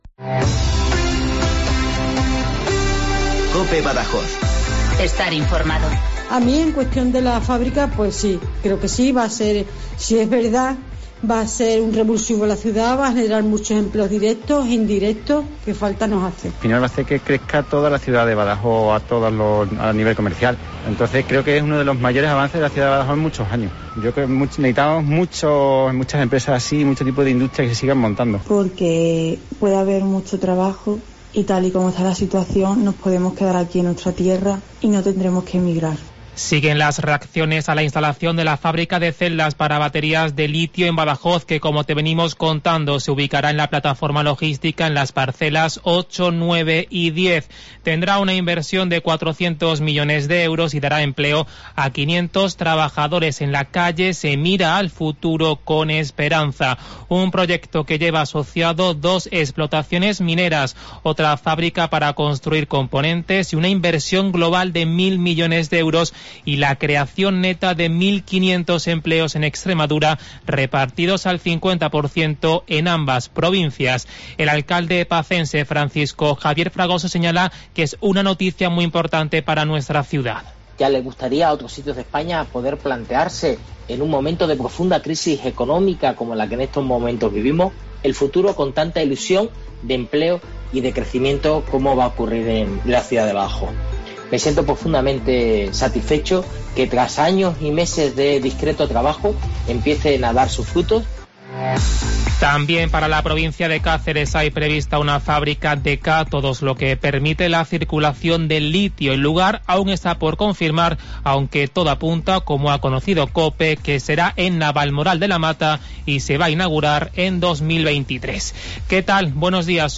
Informativo de Badajoz - Viernes, 26 de marzo de 2021